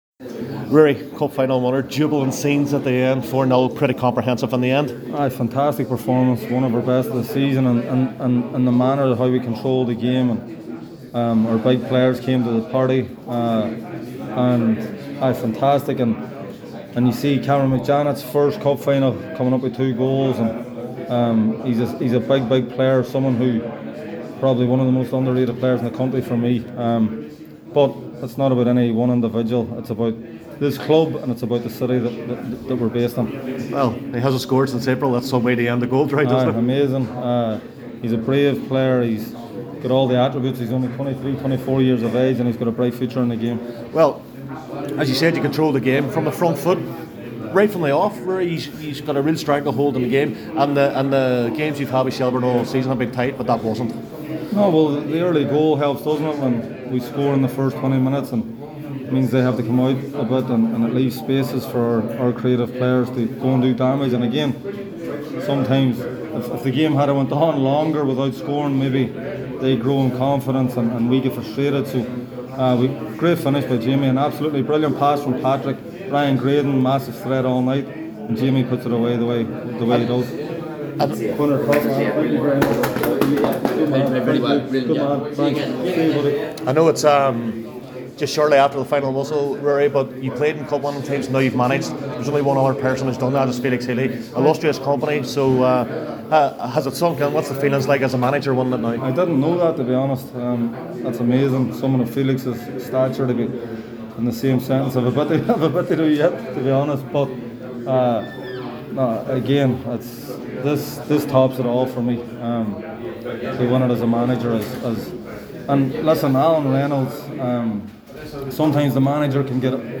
spoke to an emotional and proud Ruaidhri Higgins after the final whistle.